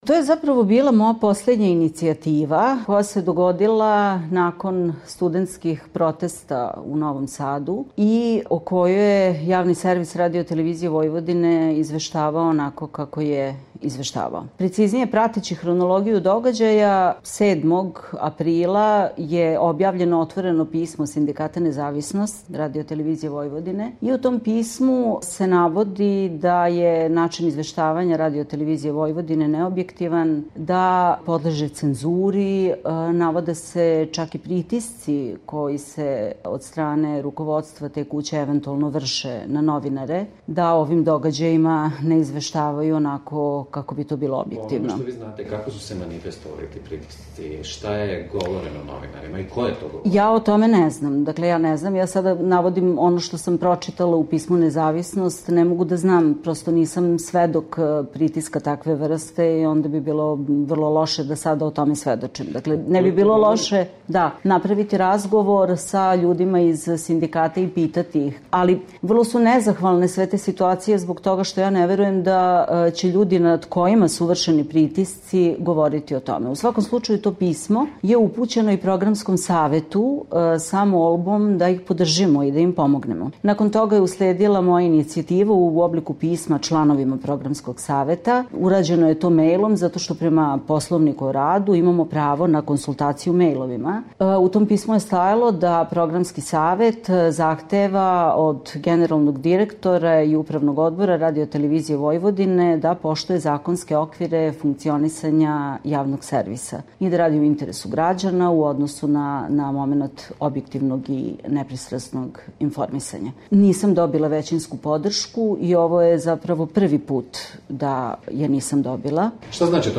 Intervjuu nedelje